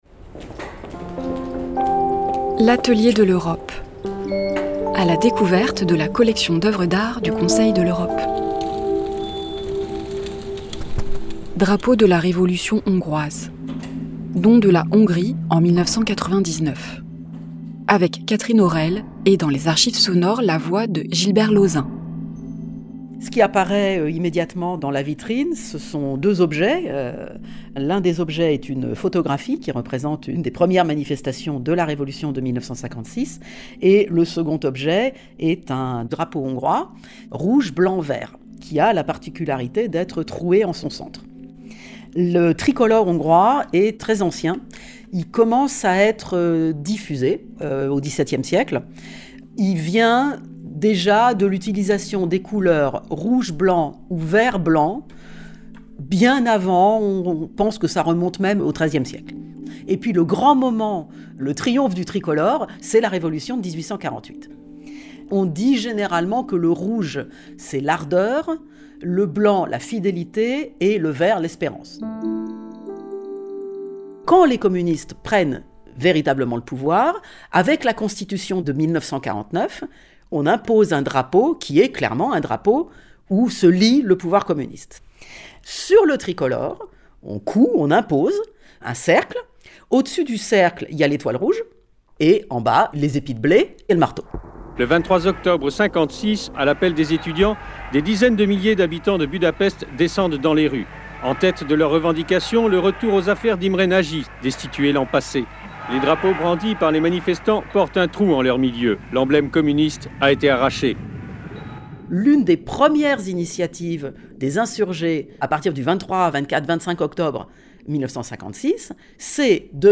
historienne